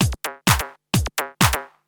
Electrohouse Loop 128 BPM (31).wav